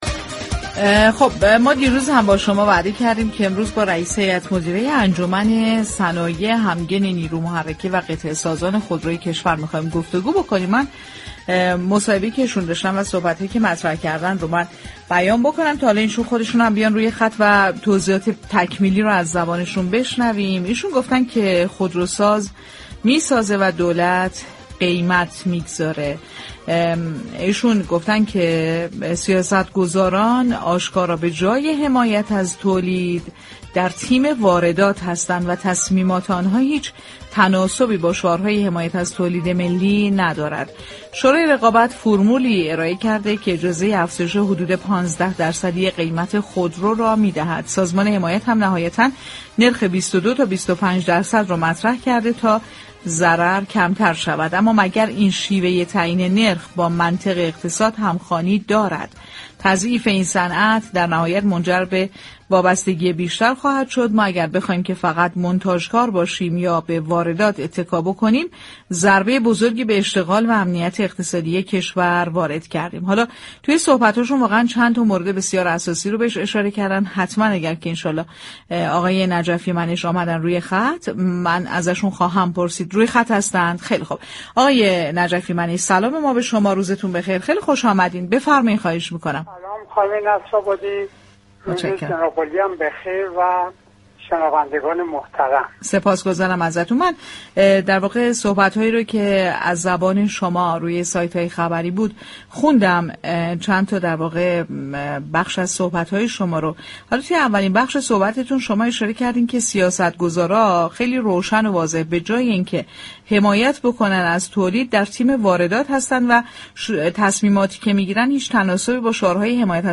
در گفت و گو با برنامه «بازار تهران»